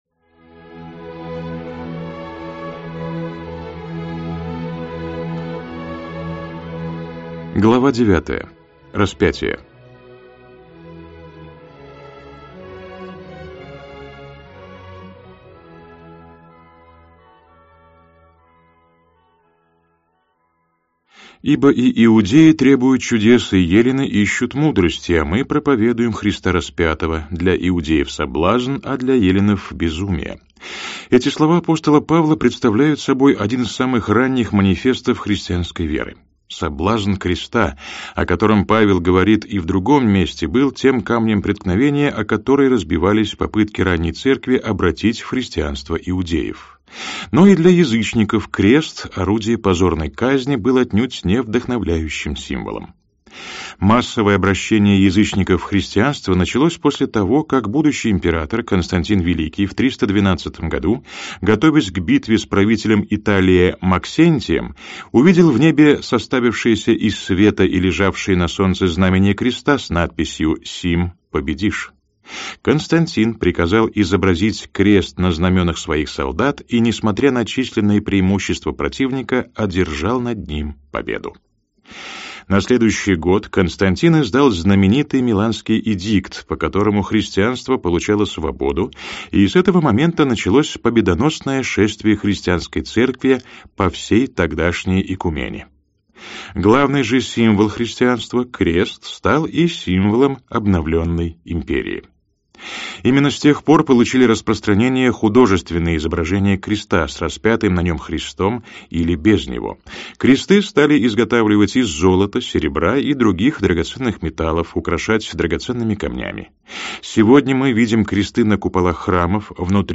Аудиокнига Иисус Христос. Жизнь и учение. Книга VI. Смерть и Воскресение. Том 5. Глава 9. Распятие | Библиотека аудиокниг